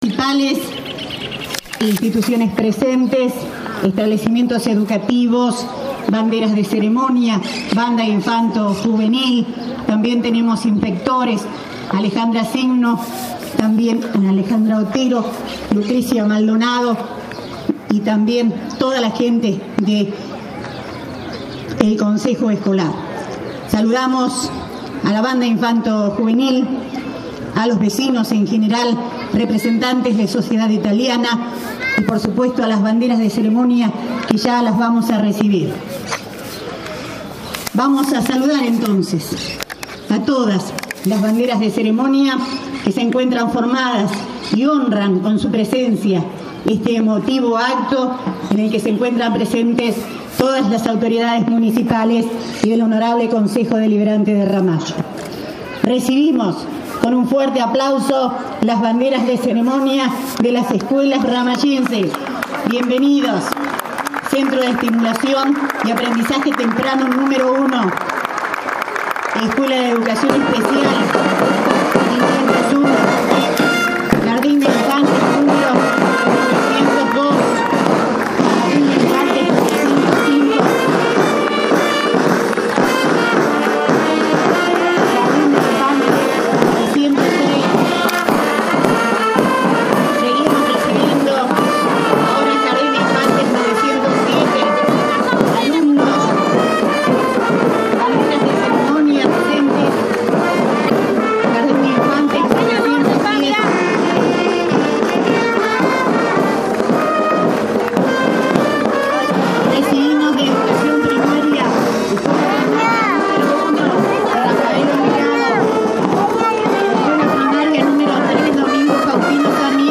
153º ANIVERSARIO DE RAMALLO. ACTO CENTRAL EN LA PLAZA PRINCIPAL JOSÉ MARÍA BUSTOS.
Audio. Apertura del Acto